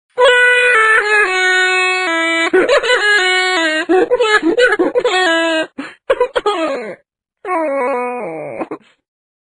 City Bus Tickle Express Bus sound effects free download